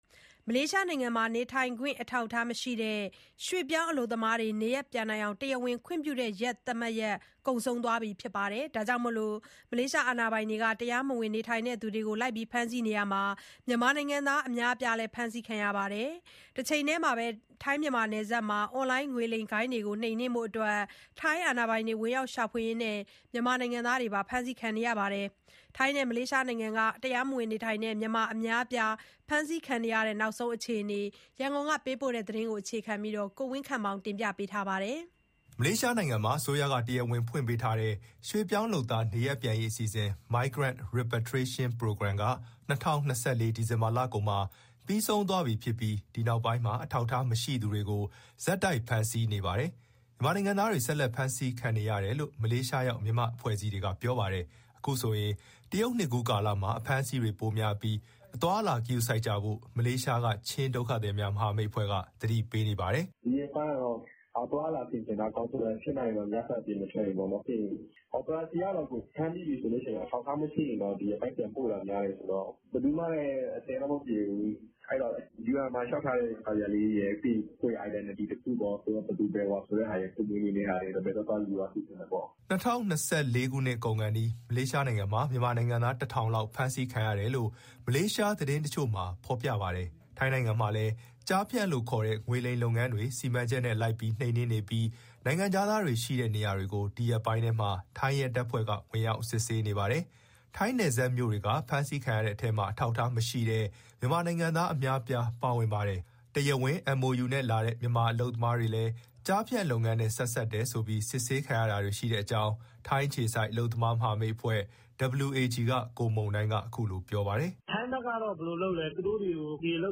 ထိုင်းနဲ့ မလေးရှားနိုင်ငံတွေက နောက်ဆုံးအခြေအနေနဲ့ ပတ်သက်လို့ ရန်ကုန်က သတင်းပေးပို့ ထားပါတယ်။